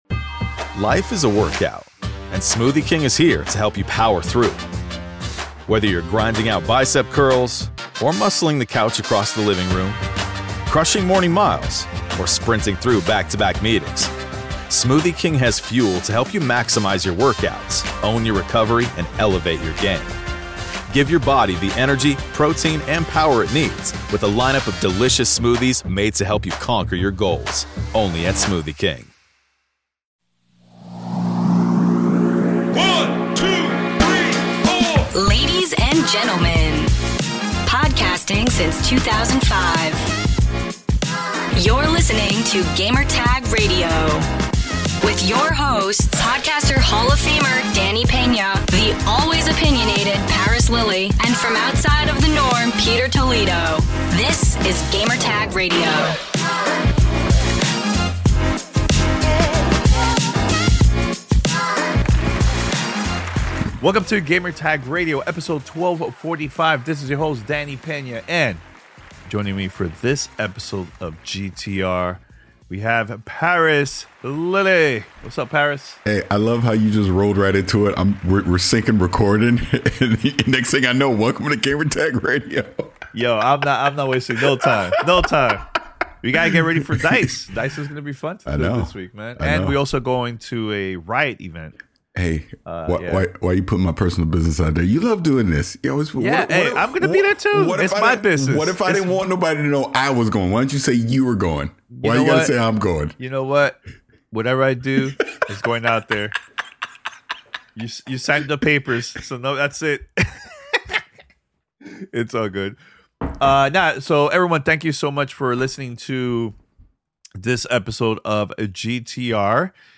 PS VR2 & Razer Edge 5G Reviews, Street Fighter 6 Interview